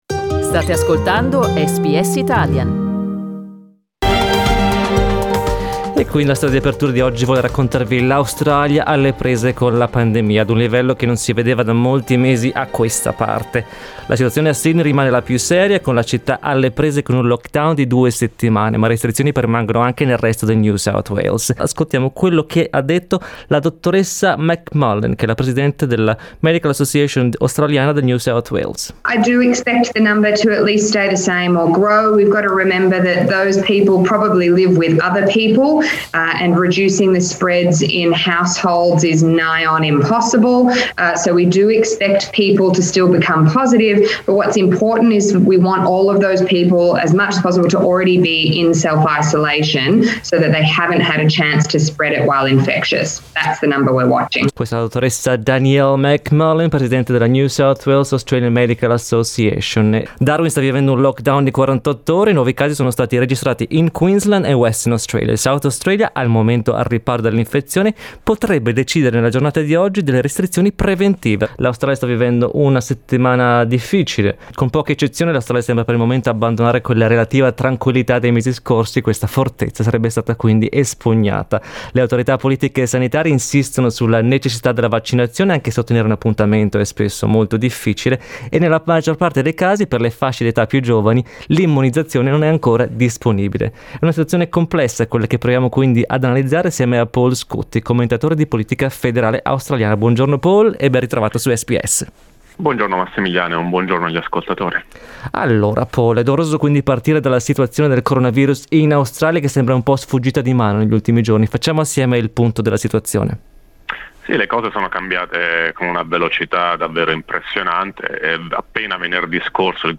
Ascolta l’aggiornamento a cura del commentatore di politica federale